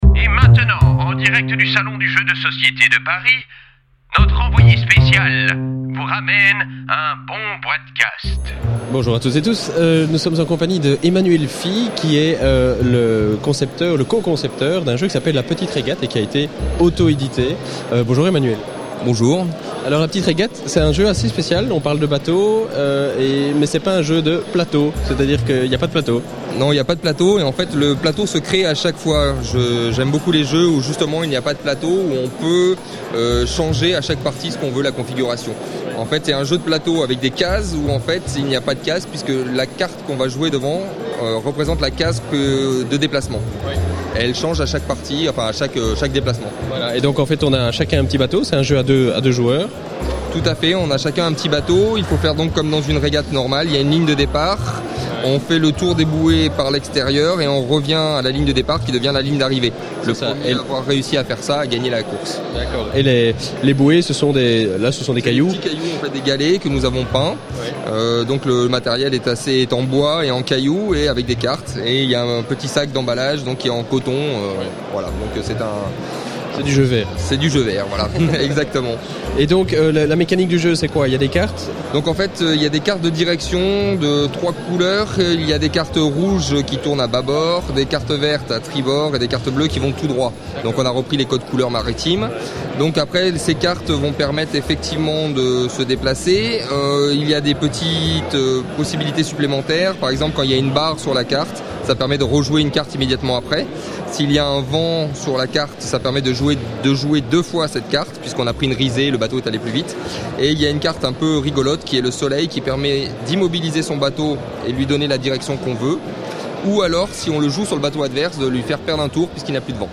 (enregistré lors du Salon du jeu de Société de Paris édition 2009)